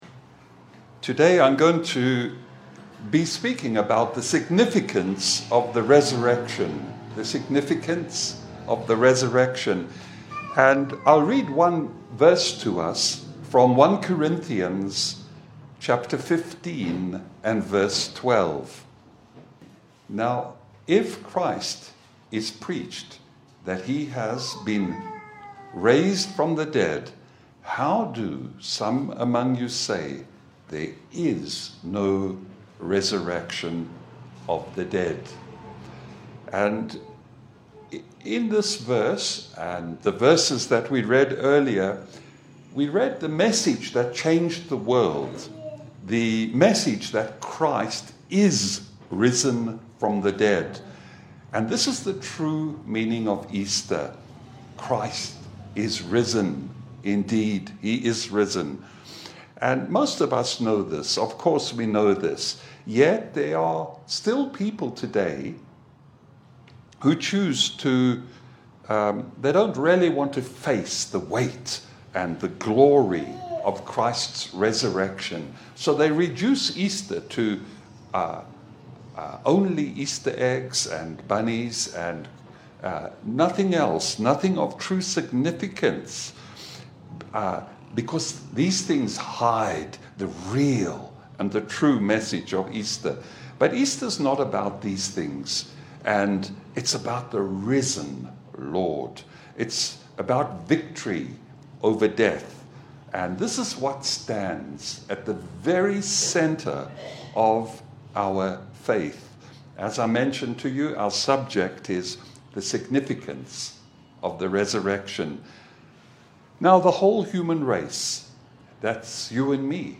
Service Type: Sunday Bible fellowship